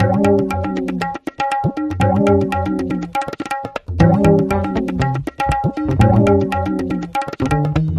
Tabla Loop